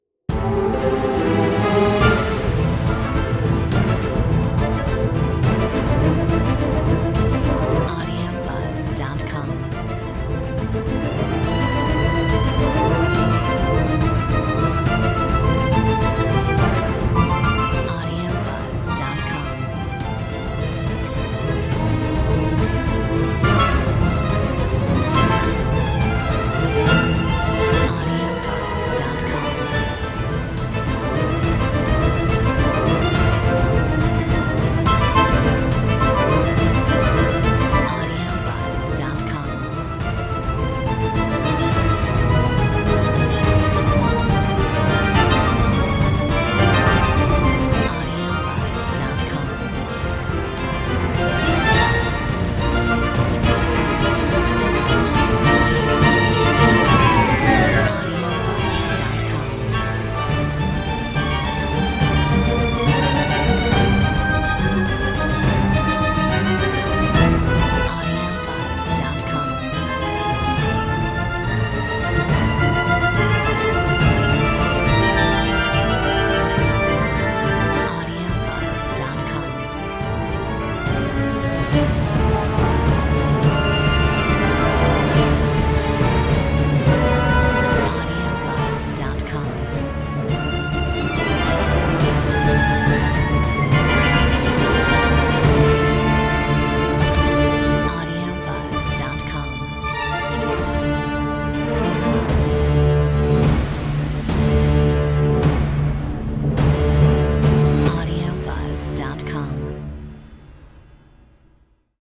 Metronome 70